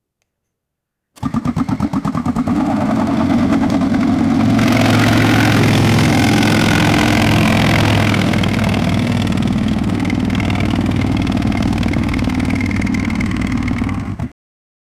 harley-davidson-motorcycl-ymyna3tz.wav